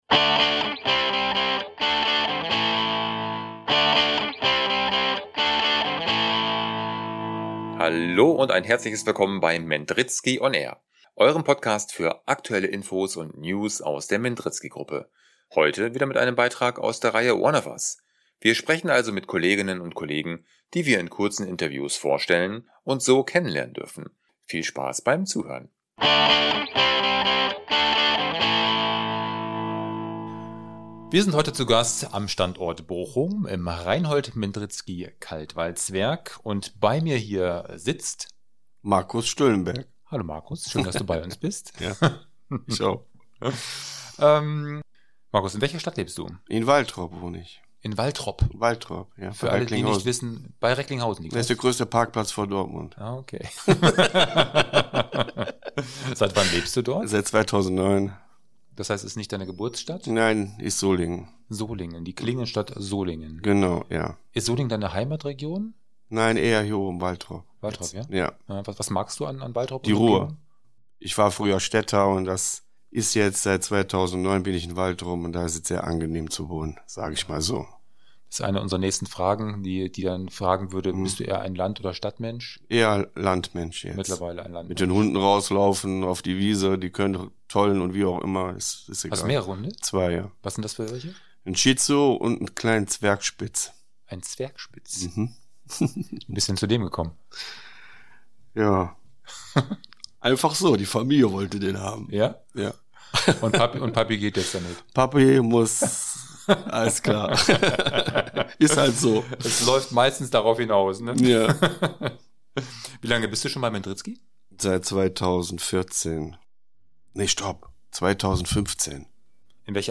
(aus unserem Kaltwalzwerk in Bochum)